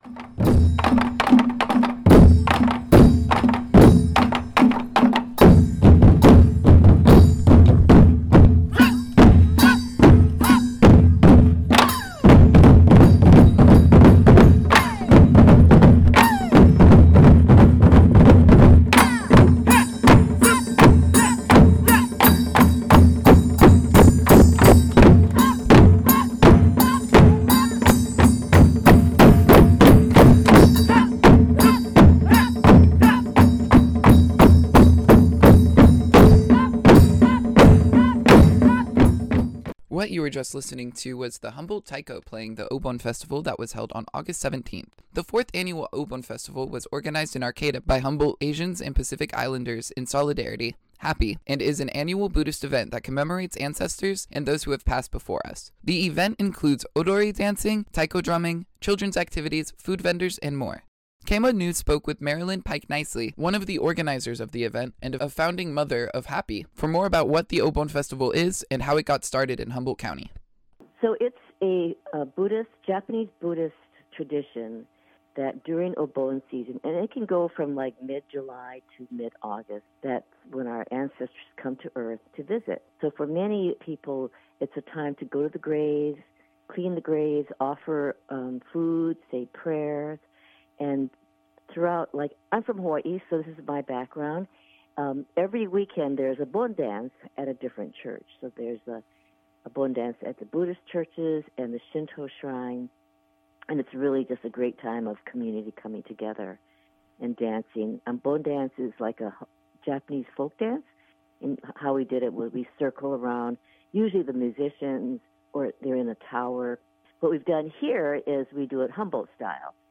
Public Affairs: Obon Festival